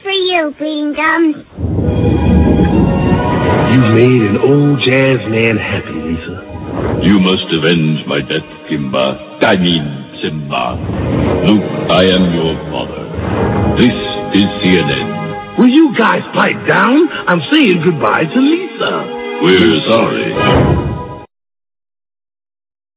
Real® Movie[101kb] Lisa's tribute to Bleedin' Gums Murphy is more successful than she could possibly have imagined. Note: the newscaster at the end of this clip is actually voiced by James Earl Jones.